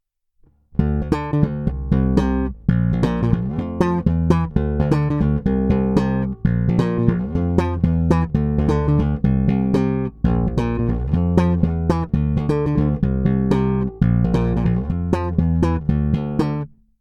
Nahráno je to rovnou do zvukovky, vše s otevřenou tónovou clonou.
Hra blízko kobylky